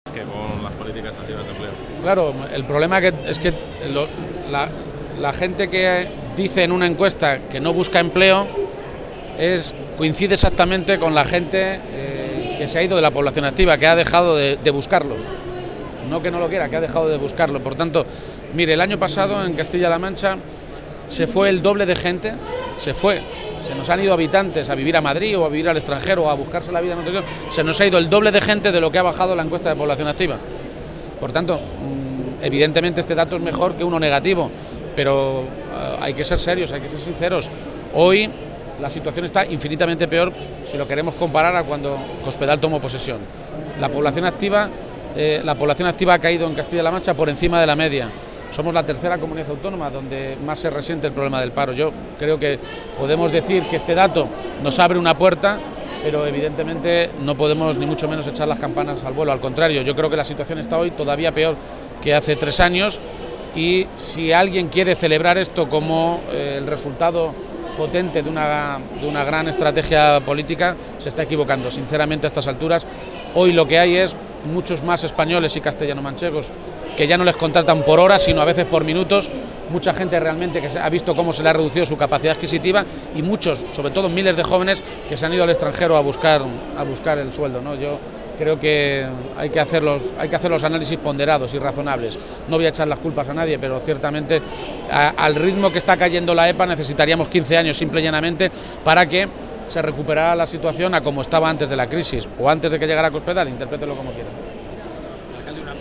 García-Page hacía estas declaraciones a preguntas de los medios de comunicación durante su visita a Fitur, la Feria Internacional del Turismo.
Cortes de audio de la rueda de prensa